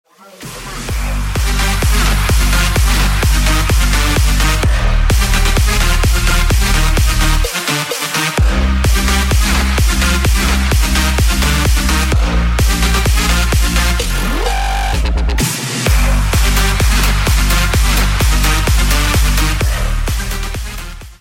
Android, Elektronisk musik